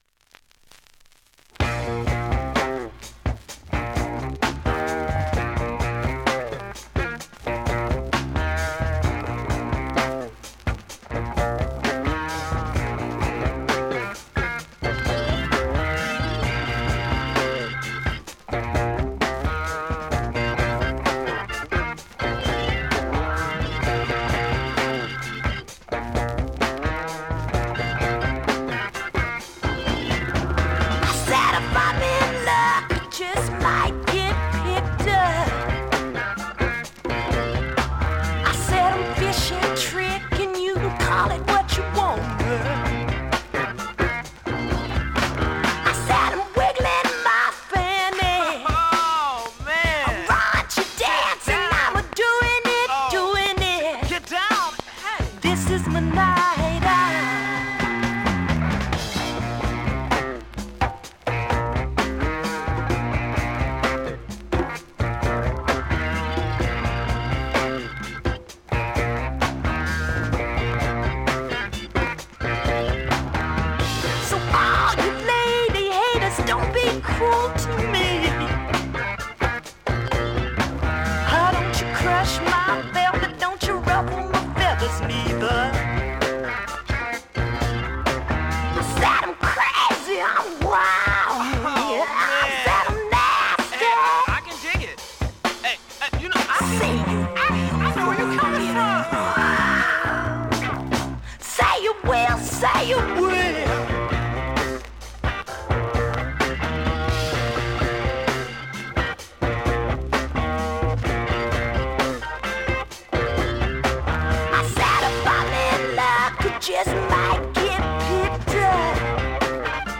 現物の試聴（両面すべて録音時間６分）できます。